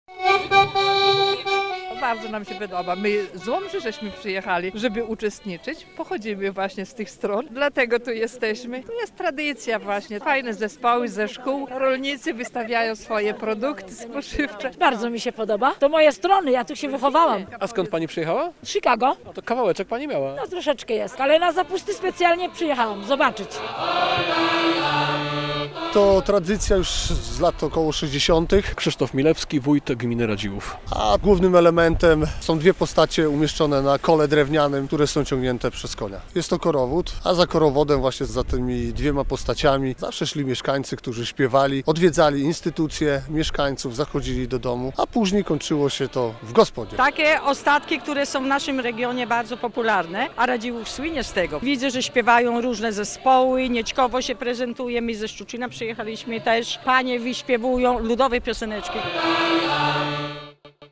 Mieszkańcy Radziłowa pożegnali karnawał - zorganizowali tradycyjne zapusty - relacja
Wójt gminy Radziłów Krzysztof Milewski mówił, że tradycja radziłowskich zapustów jest bardzo stara.